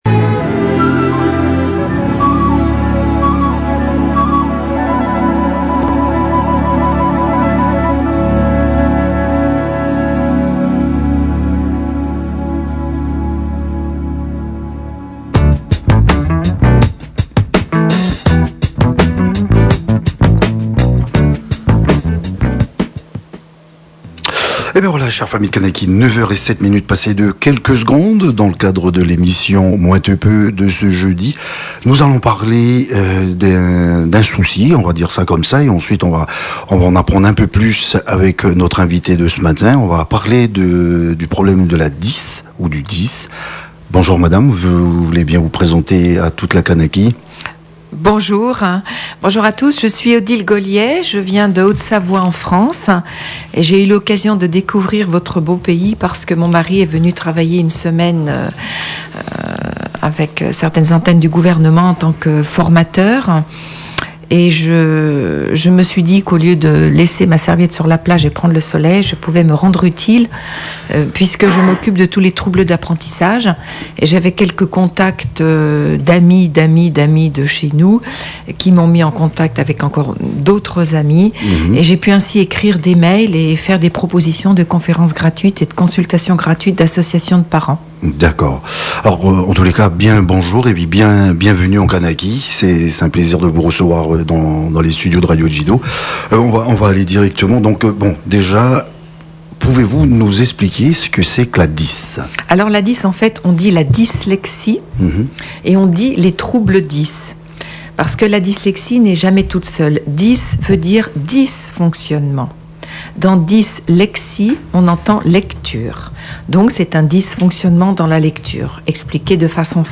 Interview par la Radio Djiido à Nouméa à l’occasion de son passage à Nouméa et de ses rencontres avec les associations de parents d’élèves et établissements scolaires.